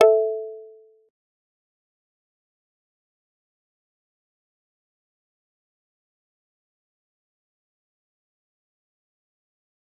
G_Kalimba-A4-pp.wav